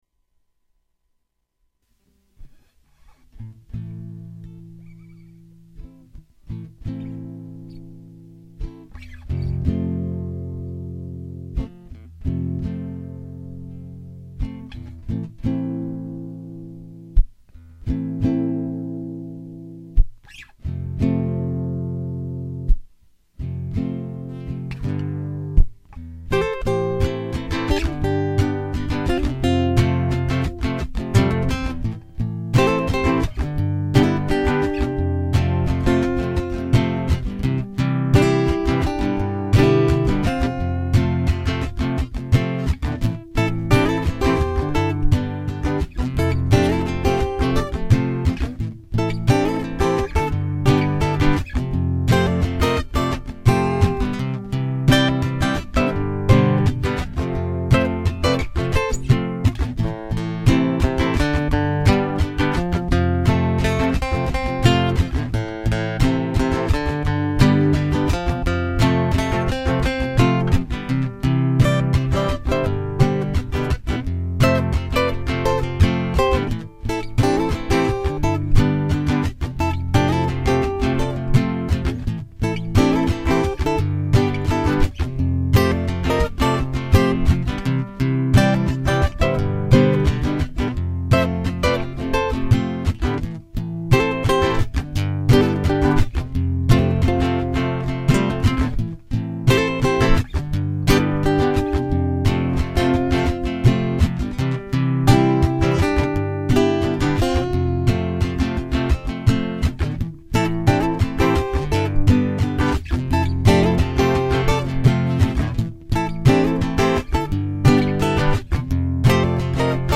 a new world of malagasy style music | Blog
gasywave_improv_feeling.mp3